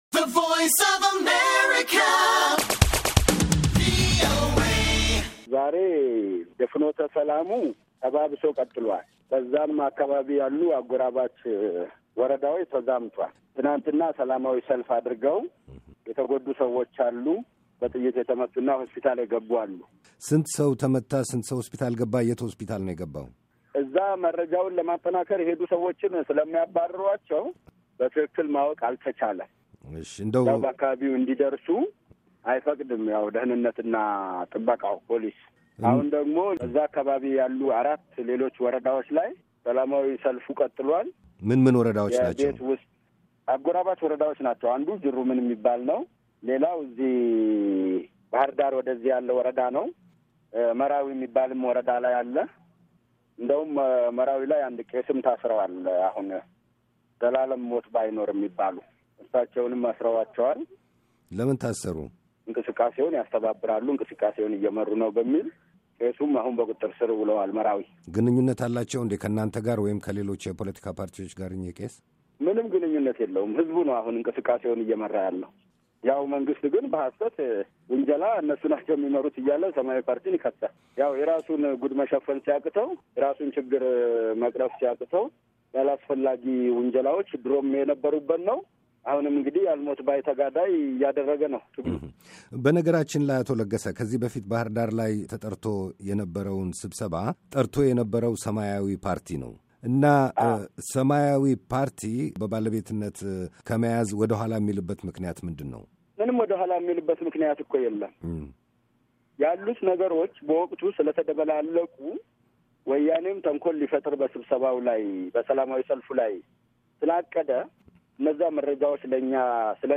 ቃለ-ምልልስ